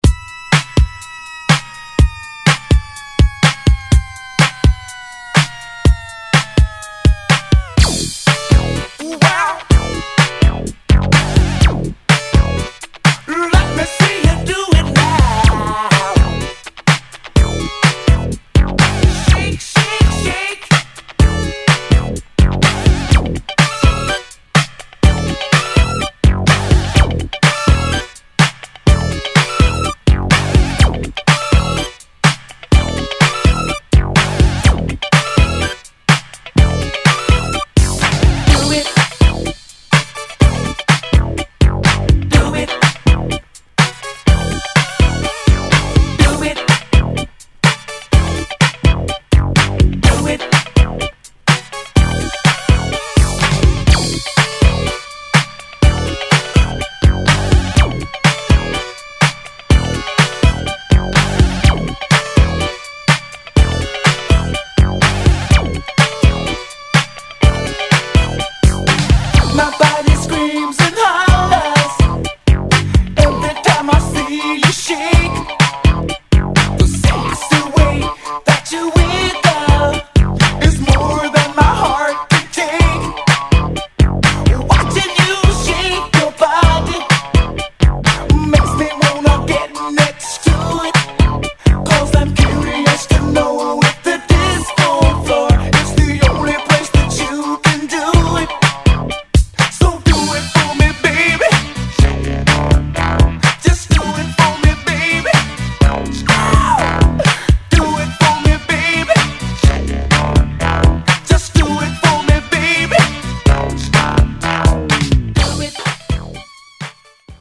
80's Disco~Funk!!